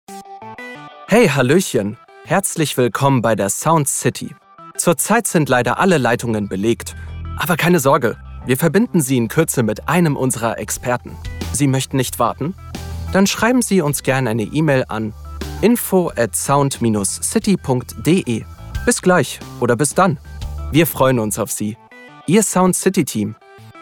Sprecher, Synchronsprecher